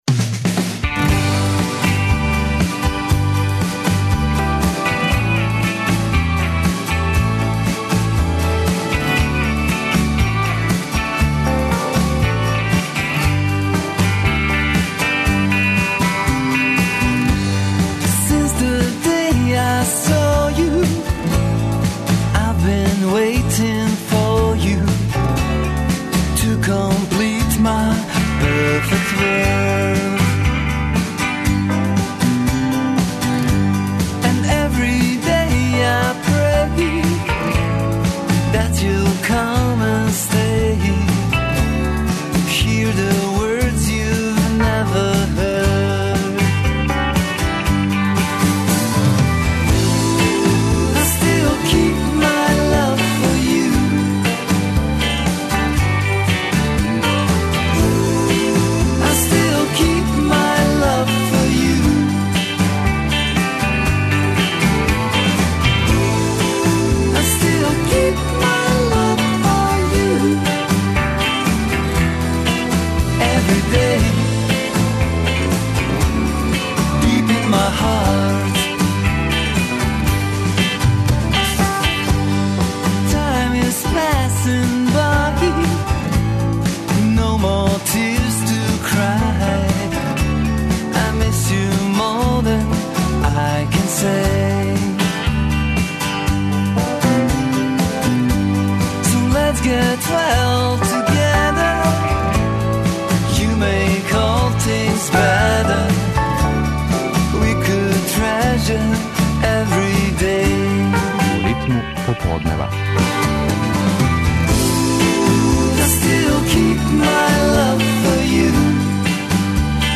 Гости су један од тренутно најбољих београдских састава - Синестезија. Бенд је настао почетком 2006. године, а на почетку свог пута био је под снажним утицајем такозваног 'Новог таласа'.